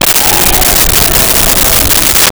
Creature Growl 07
Creature Growl 07.wav